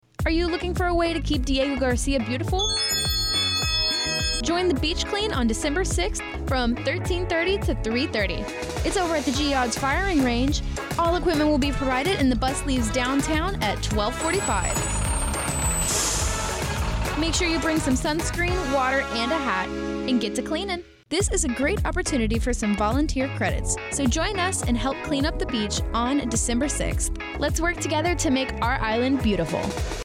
NSFDiegoGarciaRadioSpotBeach Clean